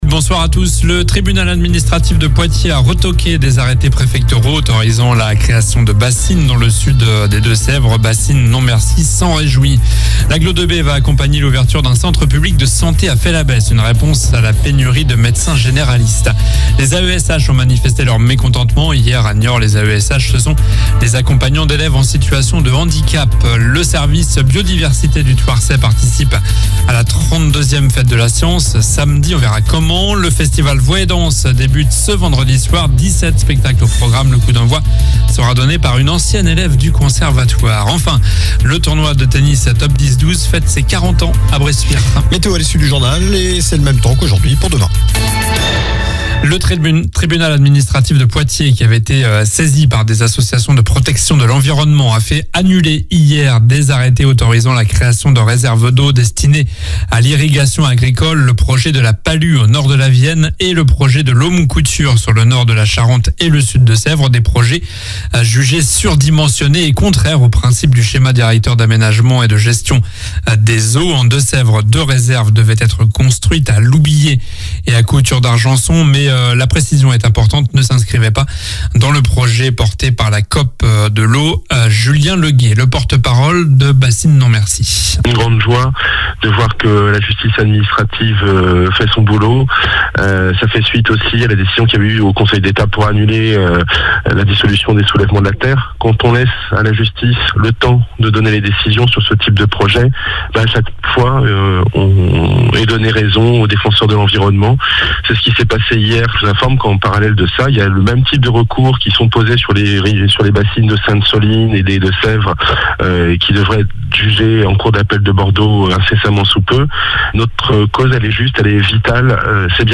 Journal du mercredi 04 octobre (soir)